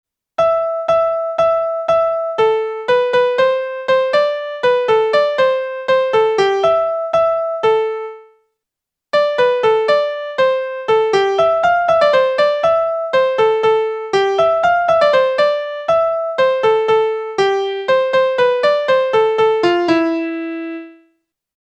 First, at the transposed pitch, finishing on d’ does not sound convincing as a resolving finalis: it sounds like it should resolve a semitone below the penultimate note, an octave below the starting note, as we see below at manuscript pitch.